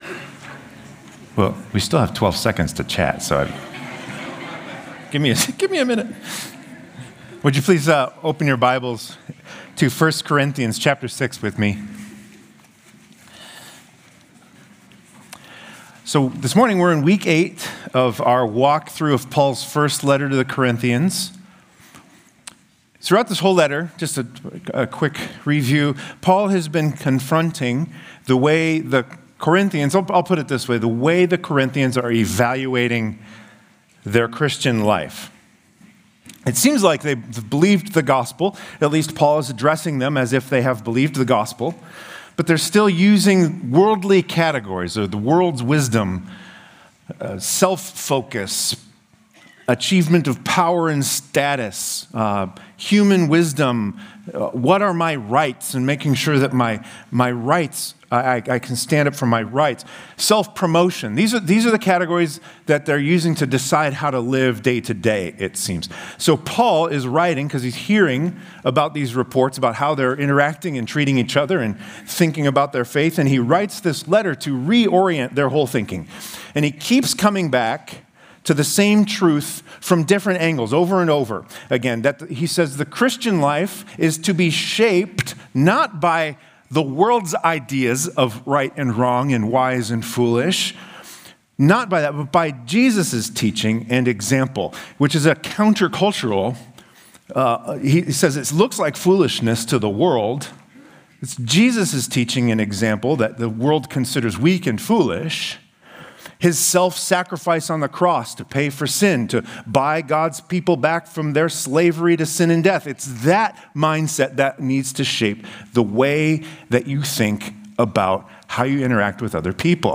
Stonebrook Sunday AM